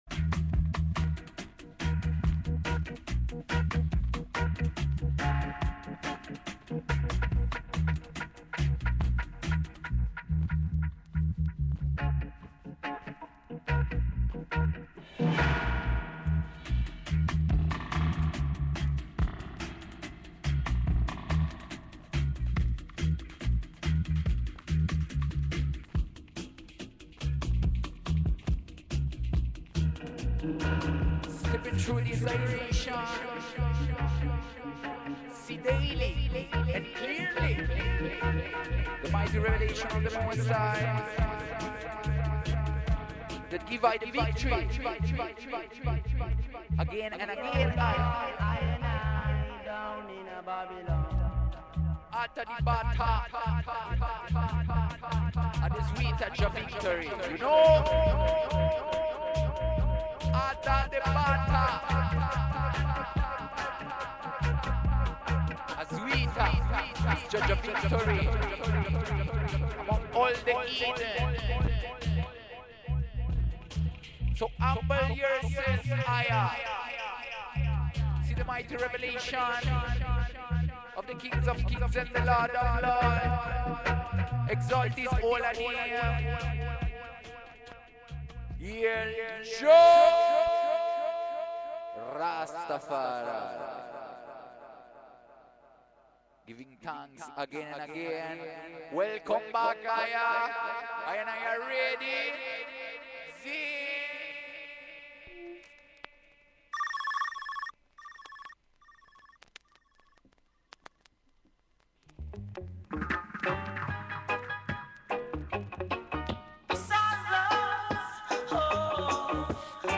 Chant Ises unto the King!